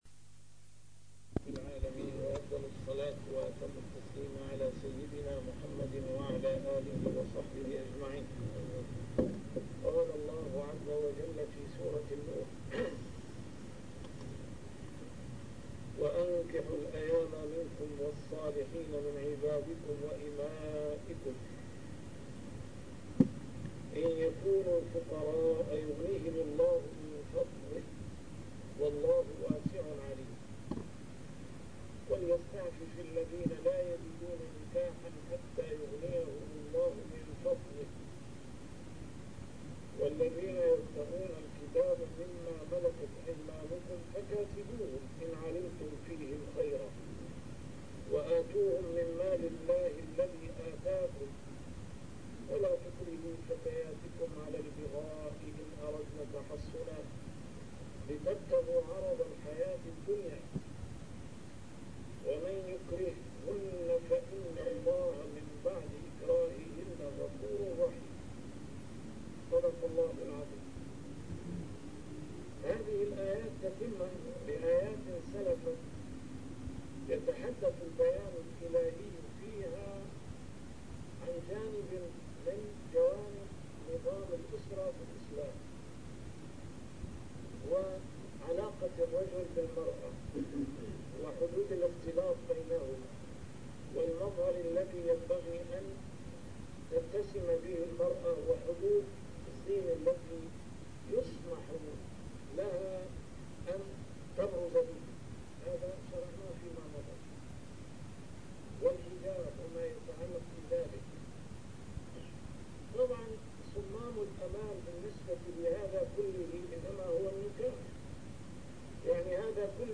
A MARTYR SCHOLAR: IMAM MUHAMMAD SAEED RAMADAN AL-BOUTI - الدروس العلمية - تفسير القرآن الكريم - تسجيل قديم - الدرس 185: النور 32-33